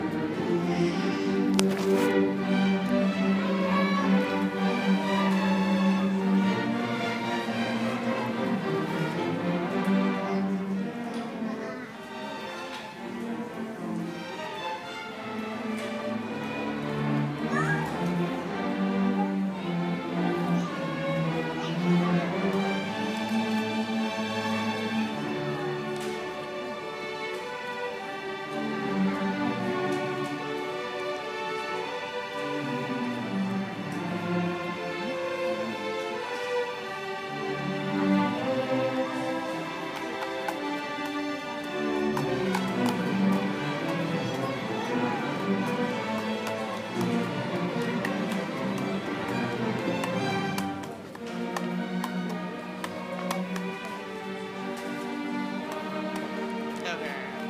orchestra-finale1.m4a